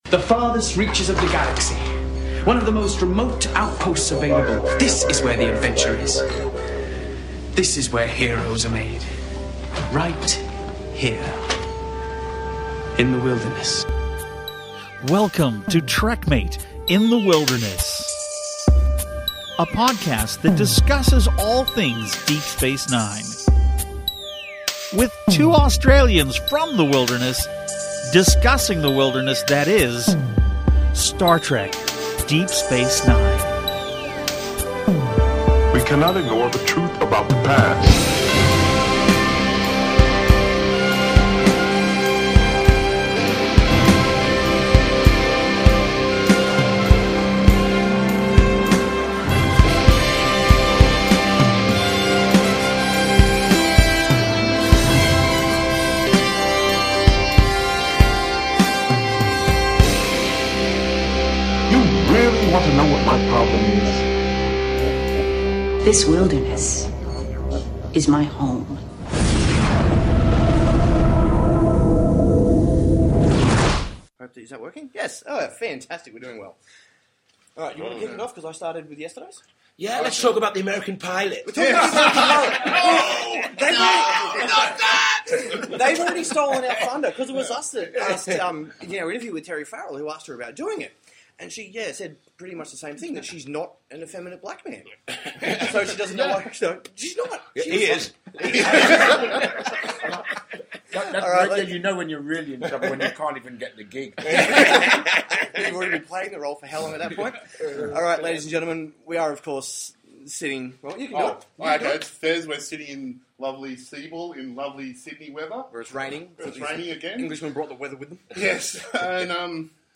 InterviewWithRedDwarfCast.mp3